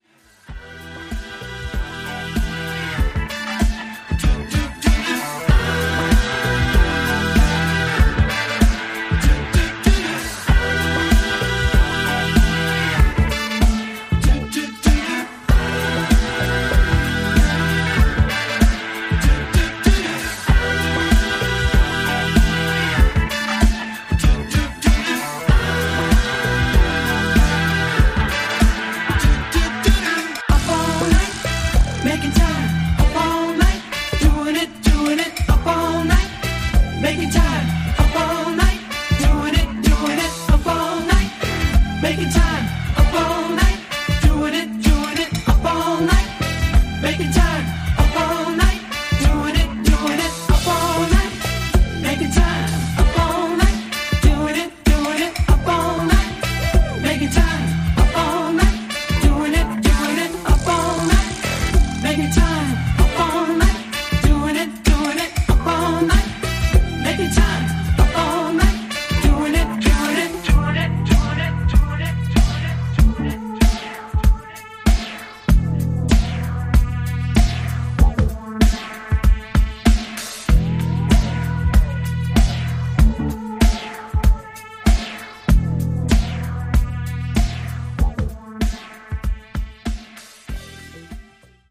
ジャンル(スタイル) NU DISCO / ITALO DISCO / EDITS